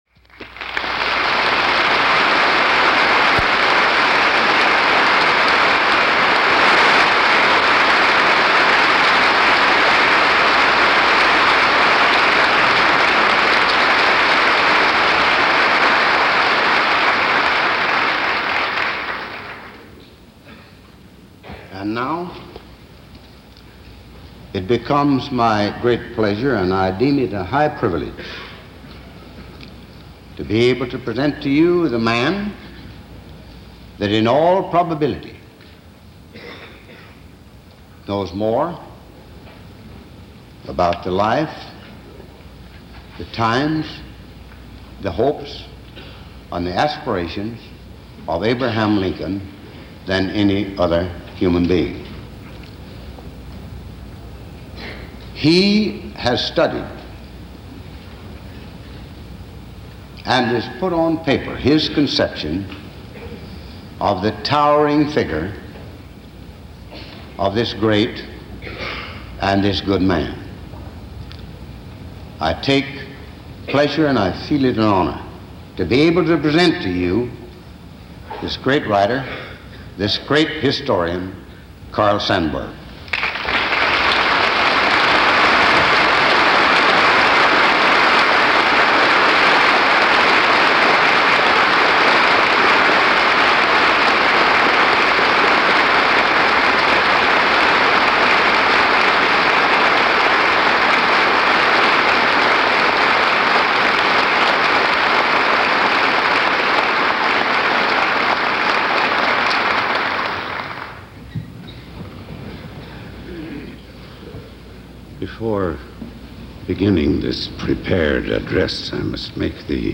Click on the link here for Audio Player – Carl Sandburg addresses Congress on occasion of Lincoln’s 150th birthday – February 12, 1959
On February 12th 1959, the great American poet and Lincoln scholar as asked to address a joint session of Congress on the occasion of Lincoln’s 150th birthday.